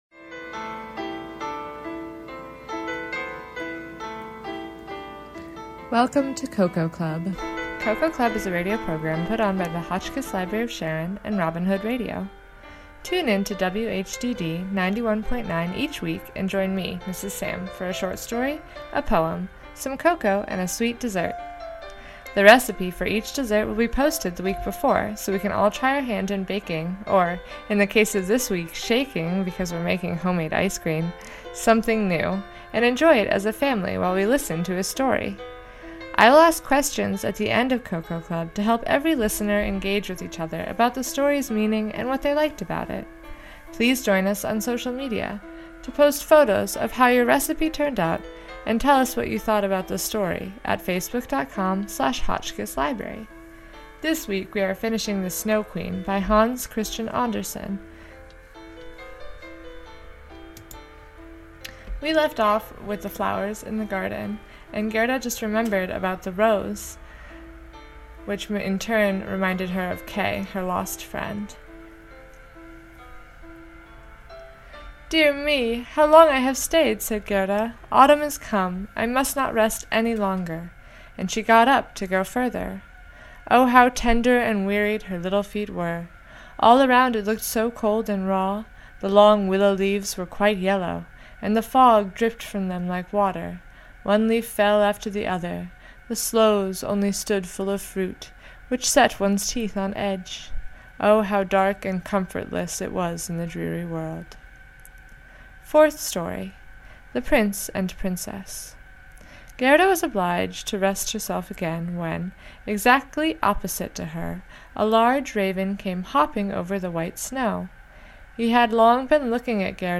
Hotchkiss Library of Sharon Cocoa Club Reading: "The Snow Queen" by Hans Christian Anderson, Part 2